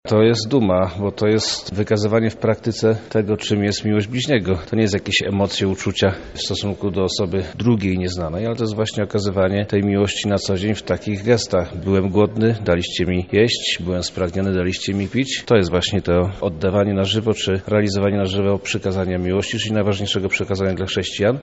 Podczas gali 25-lecia wojewoda Przemysław Czarnek podkreślał wielką rolę Bractwa: